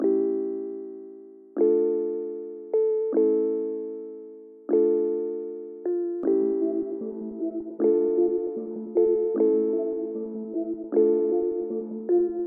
昏昏欲睡
描述：小调 迷幻和黑暗的氛围
Tag: 154 bpm Trap Loops Bells Loops 2.10 MB wav Key : D